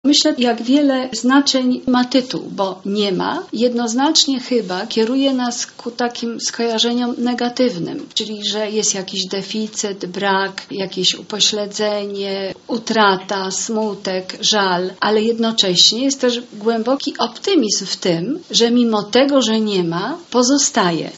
W Dzielnicowym Domu Kultury Czuby Południowe spotkaliśmy się z laureatem nagrody Nike